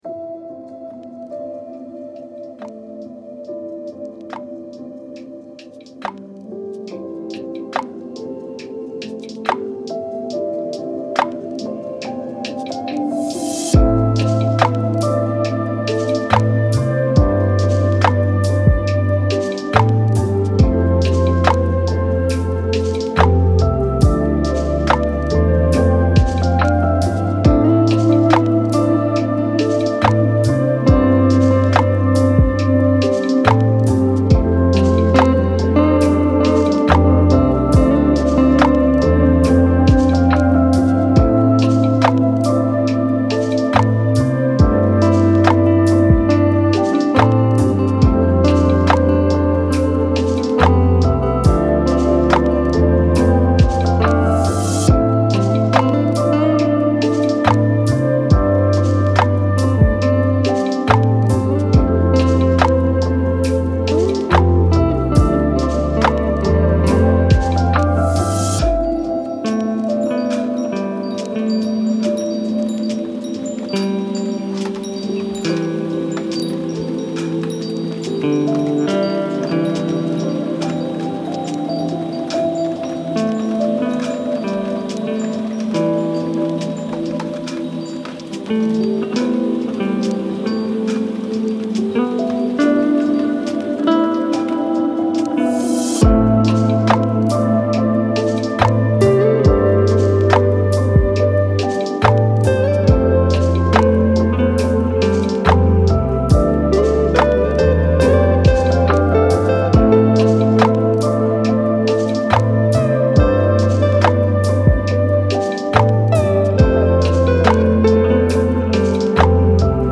Méditation Pyramide : 33+9 Hz